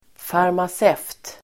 Ladda ner uttalet
farmaceut substantiv, pharmacist Uttal: [farmas'ev:t el. -sef:t]